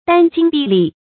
殚精毕力 dān jīng bì lì
殚精毕力发音
成语注音ㄉㄢ ㄐㄧㄥ ㄅㄧˋ ㄌㄧˋ